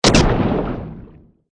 Royalty free sounds: Firearms
mf_SE-5437-harpoon_shot_2.mp3